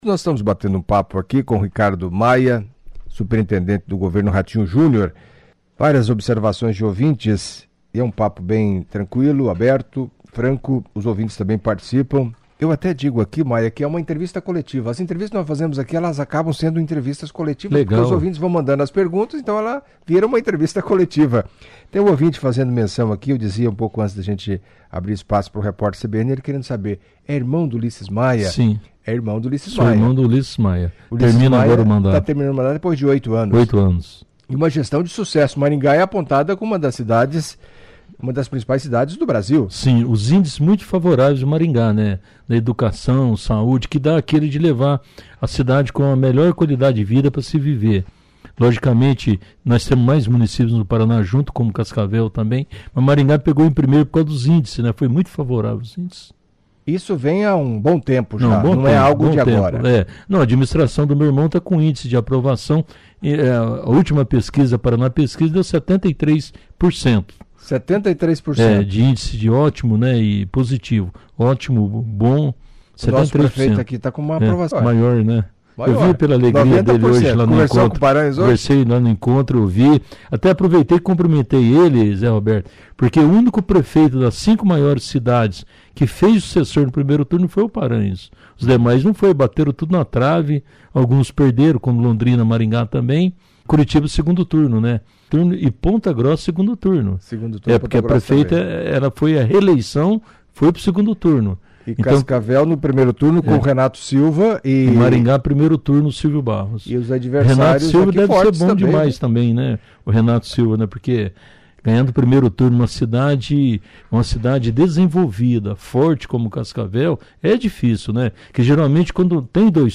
Em entrevista à CBN Cascavel nesta sexta-feira (22) Ricardo Maia, Superintendente do Governo Ratinho Júnior, falou do evento realizado na Amop, durante boa parte da manhã, que reuniu prefeitos eleitos e reeleitos, promovido pela Associação dos Municípios do Paraná (AMP) e também destacou a atenção do governador com todos os prefeitos e todos os municípios do Paraná.